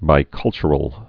(bī-kŭlchər-əl)